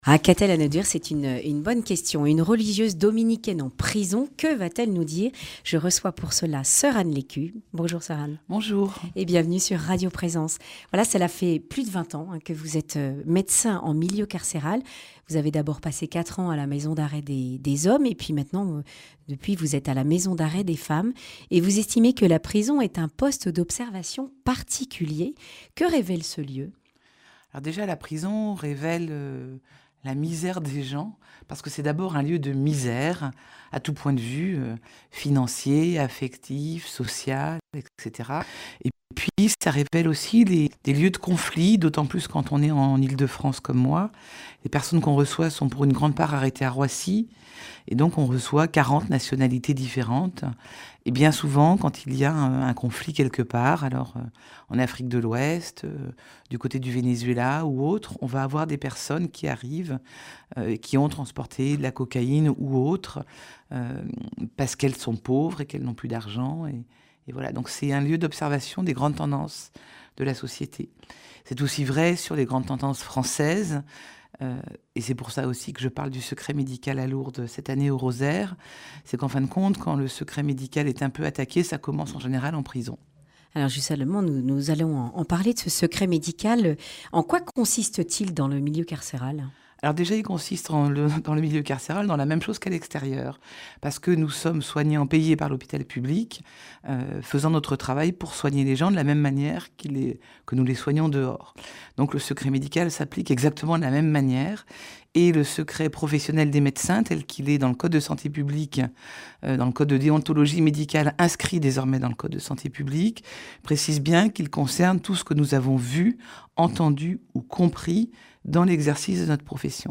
vendredi 6 octobre 2023 Le grand entretien Durée 11 min